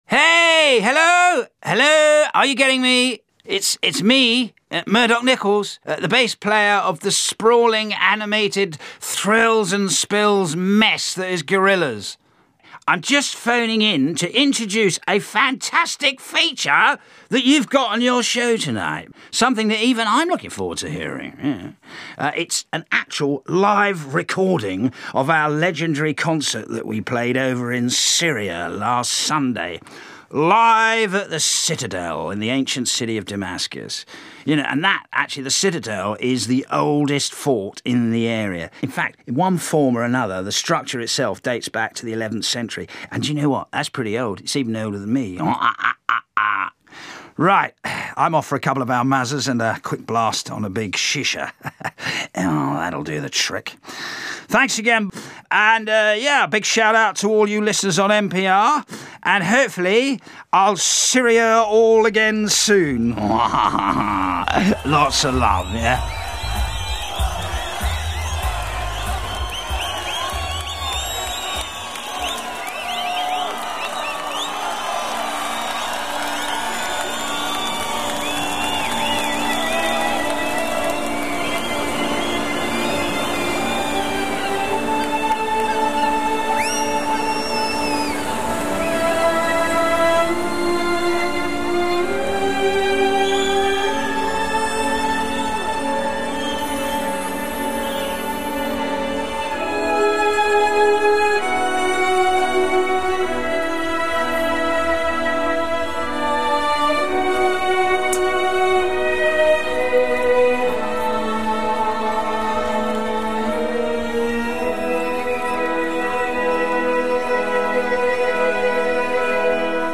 onstage at the Citadel of Damascus